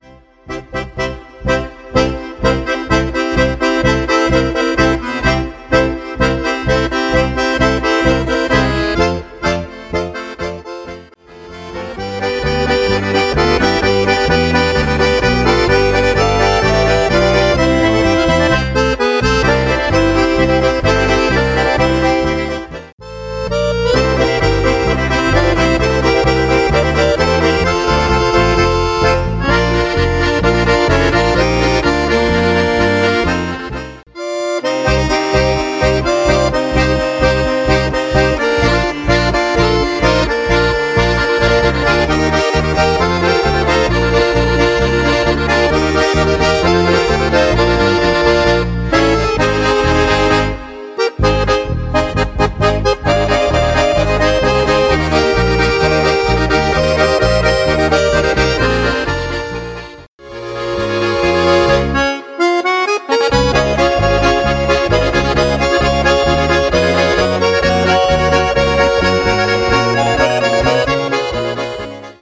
Potpourri